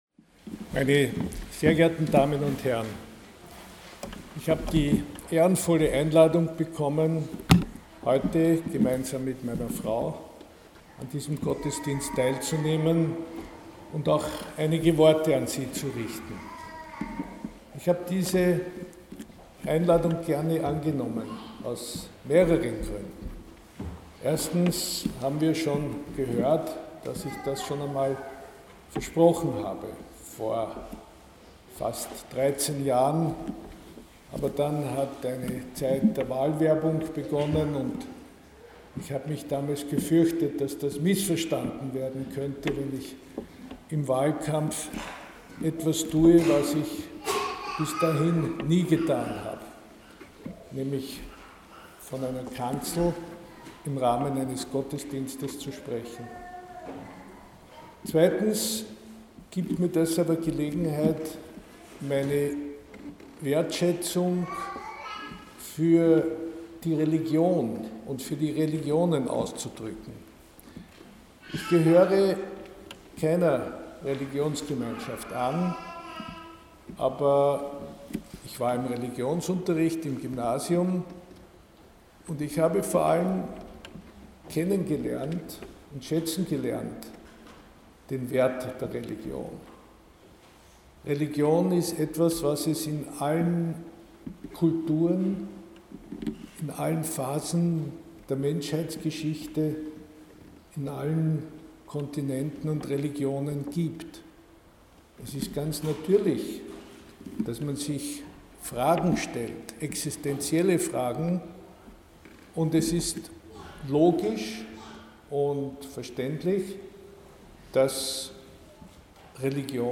Alt-Bundespräsident als Gastprediger in evangelischer Kirche